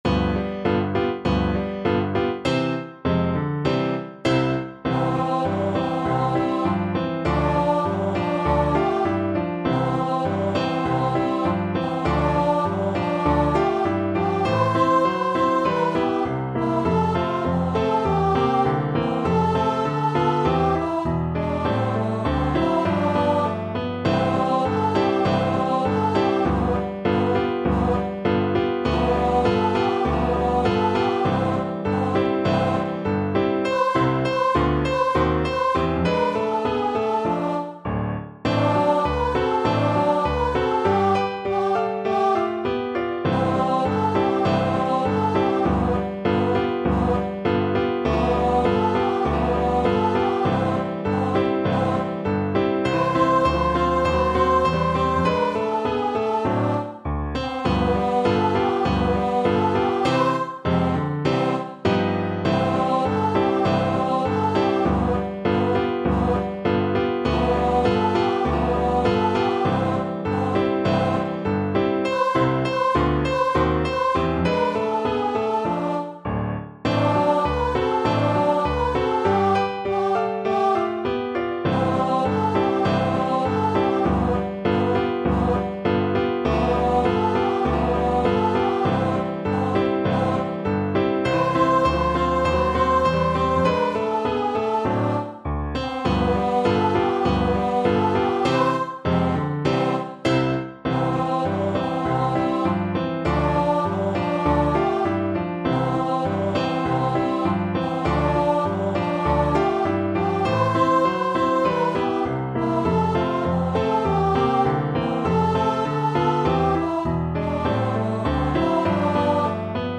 Moderato =c.100
Pop (View more Pop Voice Music)